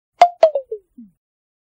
На этой странице собраны звуки Apple AirPods: сигналы подключения, уведомления о батарее, системные эффекты и другие аудиоэлементы.
Звук AirPods при разряженной батарее